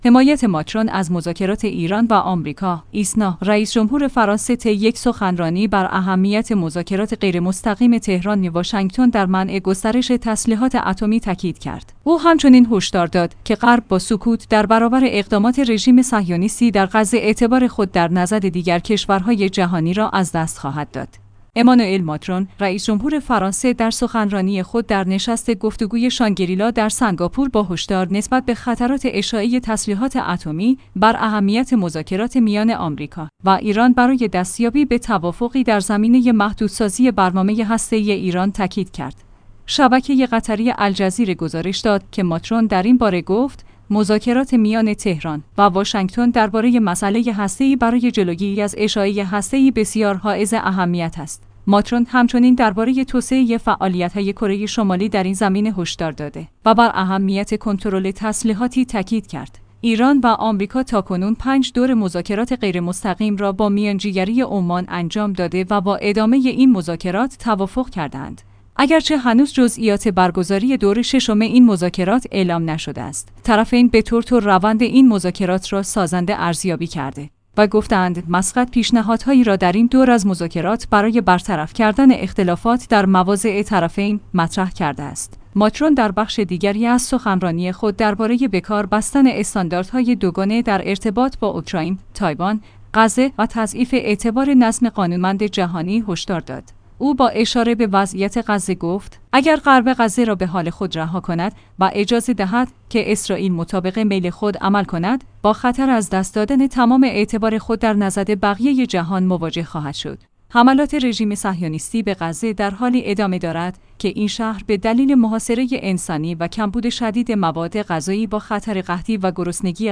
ایسنا/ رئیس‌جمهور فرانسه طی یک سخنرانی بر اهمیت مذاکرات غیرمستقیم تهران-واشنگتن در منع گسترش تسلیحات اتمی تاکید کرد. او همچنین هشدار داد که غرب با سکوت در برابر اقدامات رژیم صهیونیستی در غزه اعتبار خود در نزد دیگر کشورهای جهانی را از دست خواهد داد.